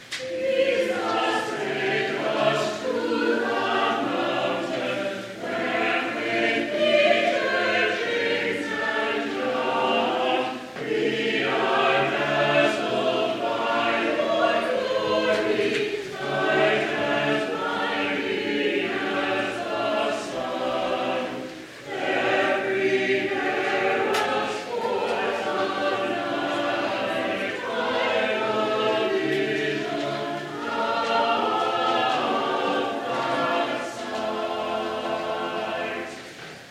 Jesus Take Us to the Mountain Feb. 7, 2016 Introit Download file Jesus Take Us to the Mountain